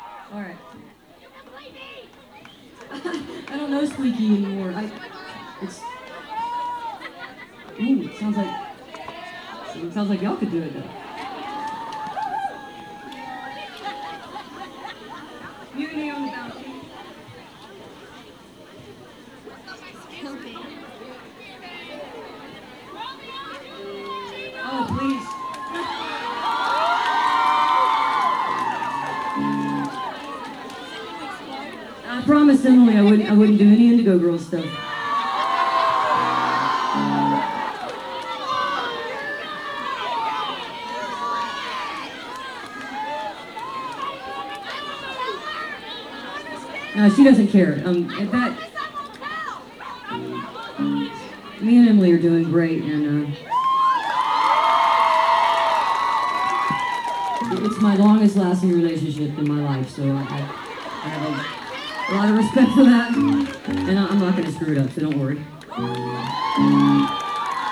lifeblood: bootlegs: 2001-04-03: cat's cradle - carrboro, north carolina (amy ray and the butchies)
13. talking with the crowd (1:07)